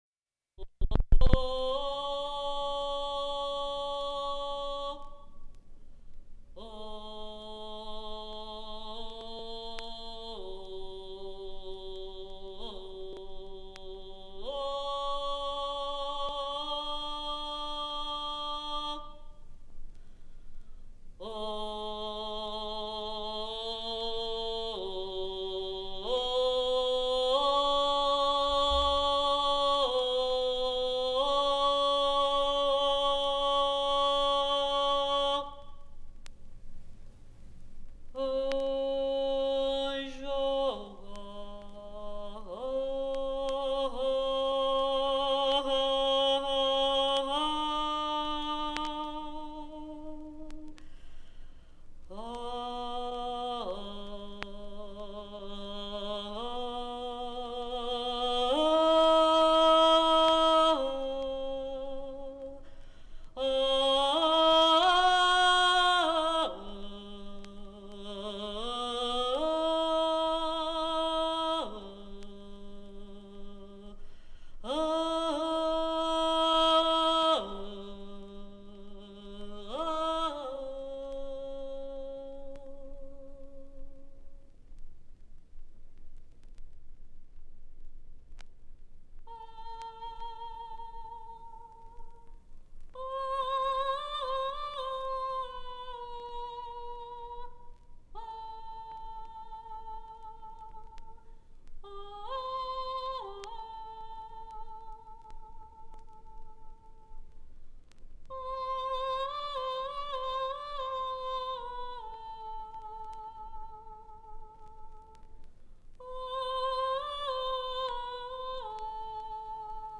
Melodias indígenas.